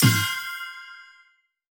normal-spinnerbonus.wav